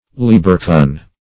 (l[=e]"b[~e]r*k[.u]n)